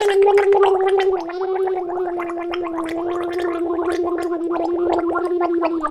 Haciendo gárgaras
Grabación sonora del sonido de una persona haciendo gárgaras (enjuagando su garganta con agua o algún tipo de líquido)
Sonidos: Acciones humanas